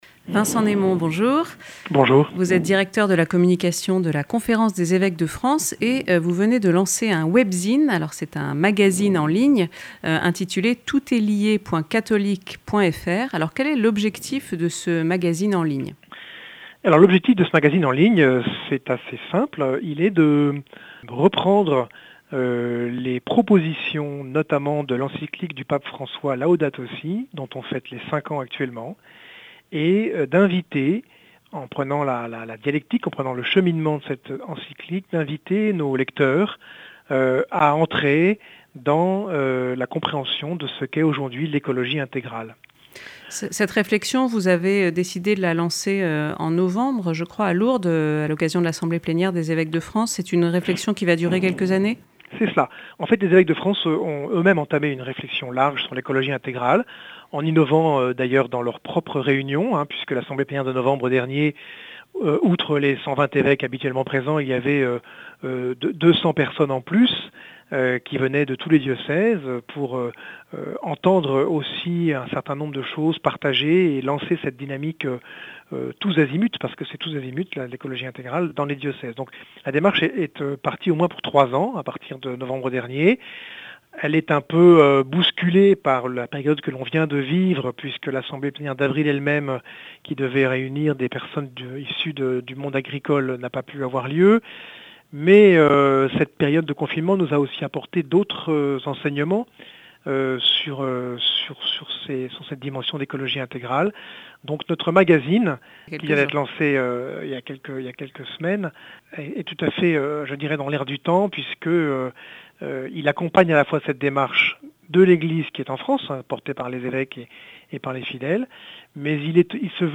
Accueil \ Emissions \ Infos \ Interviews et reportages \ « Tout est lié » le webzine de l’écologie intégrale publié par la Conférence des (...)